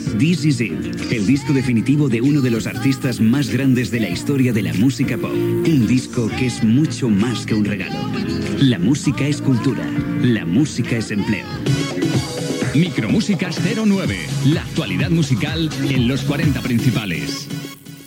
Comentari sobre un disc i indicatiu.